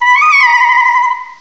cry_not_meloetta.aif